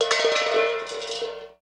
Hubcaps
Hubcap On Asphalt